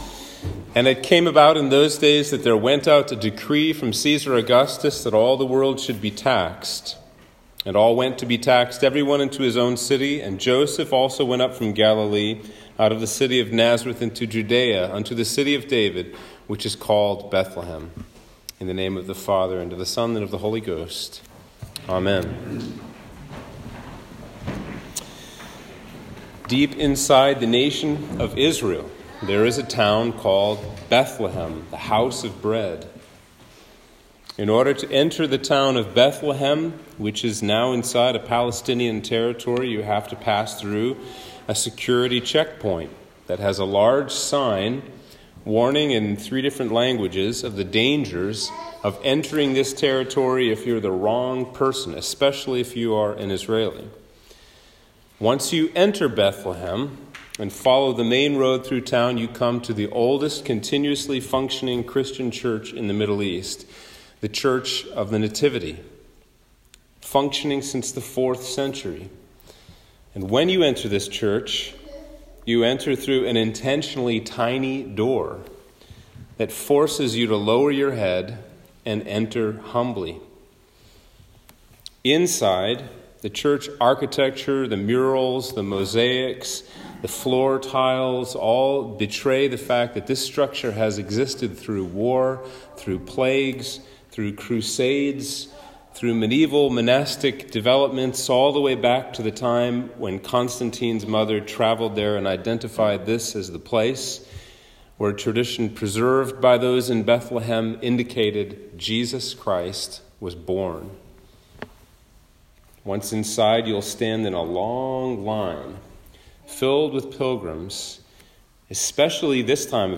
Sermon for Christmas Eve - 2021